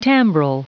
Prononciation du mot timbral en anglais (fichier audio)
Prononciation du mot : timbral